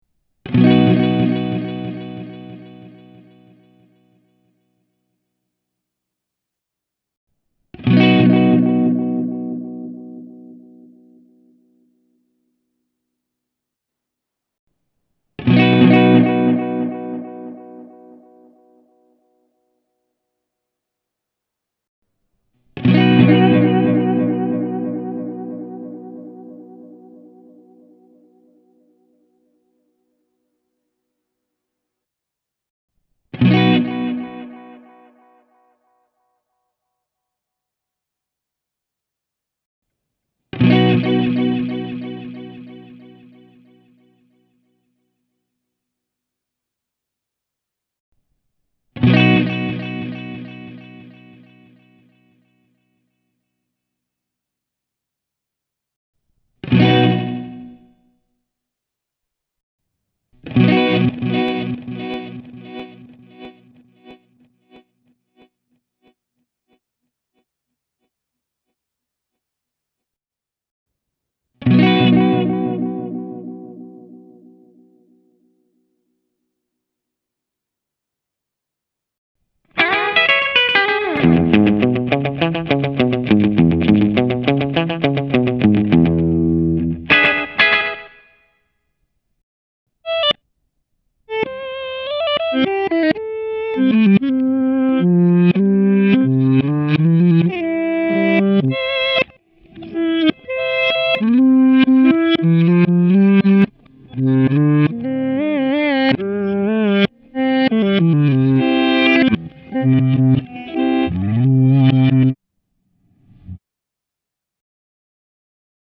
Audioklipissä soitan ensin kaikki viive-tyypit 2290:ista alkaen läpi samoilla asetuksilla. Pätkän lopussa tulevat vielä SLP- ja RVS-tyyppien esimerkkejä pidemmillä viiveajoilla:
tc-electronic-e28093-alter-ego-delay.mp3